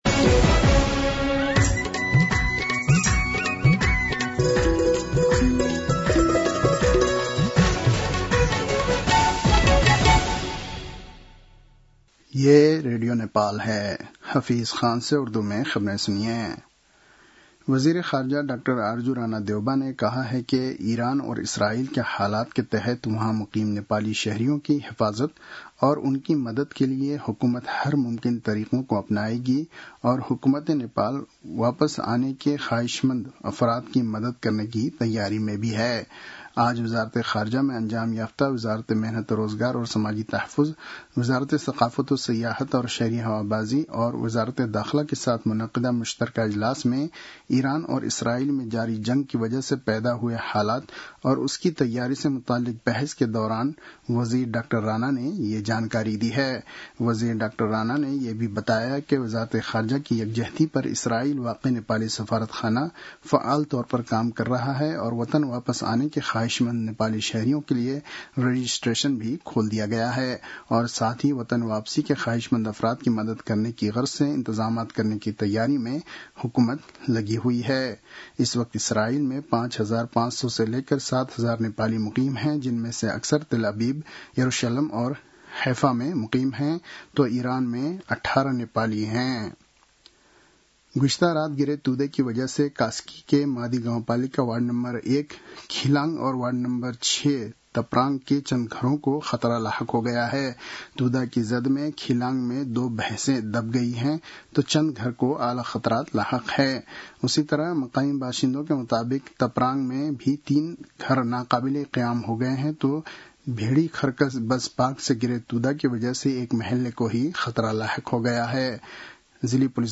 उर्दु भाषामा समाचार : ६ असार , २०८२